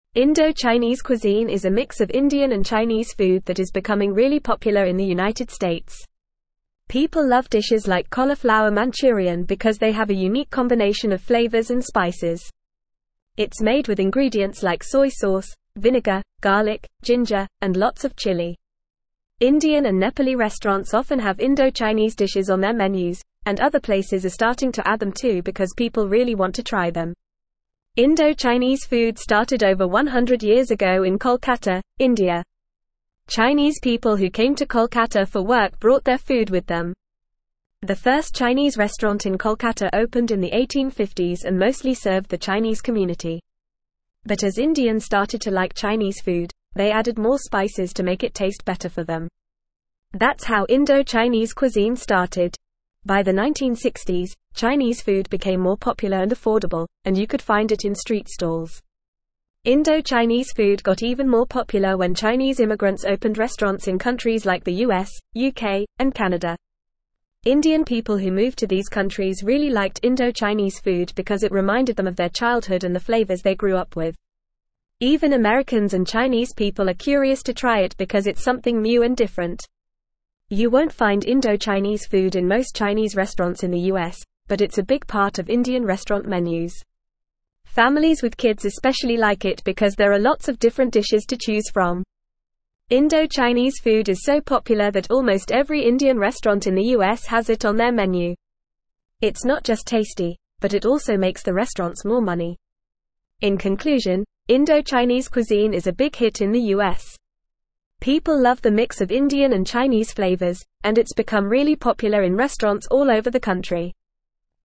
Fast
English-Newsroom-Upper-Intermediate-FAST-Reading-Indo-Chinese-cuisine-gaining-popularity-in-US-restaurants.mp3